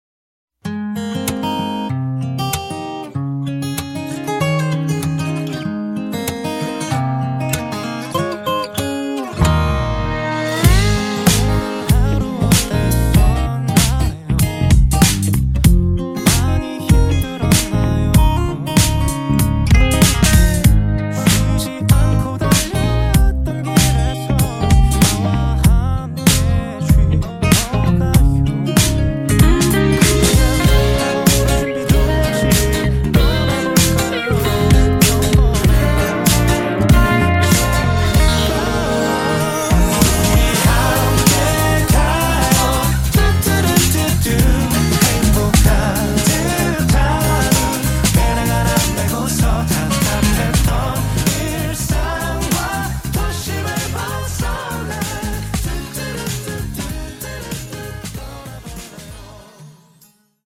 음정 원키 3:18
장르 가요 구분 Voice MR
보이스 MR은 가이드 보컬이 포함되어 있어 유용합니다.